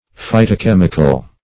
Phytochemical \Phy`to*chem"ic*al\, a.
phytochemical.mp3